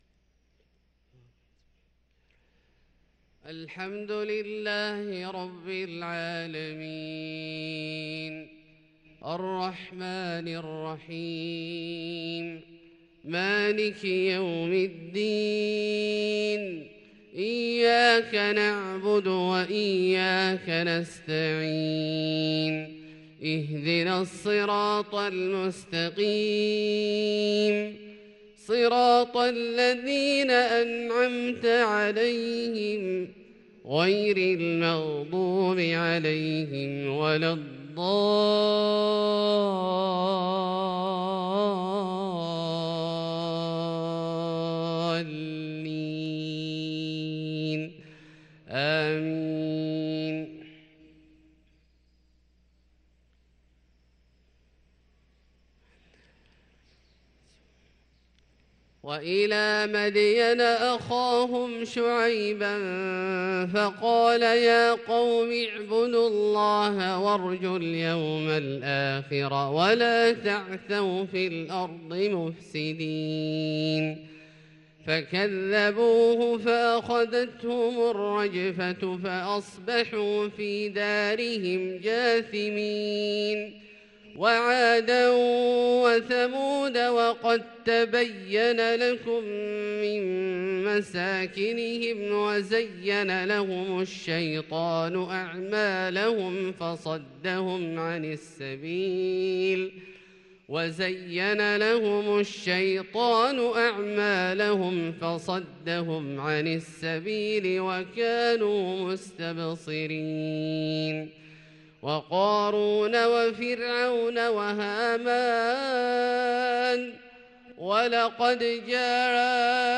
صلاة الفجر للقارئ عبدالله الجهني 19 جمادي الأول 1444 هـ
تِلَاوَات الْحَرَمَيْن .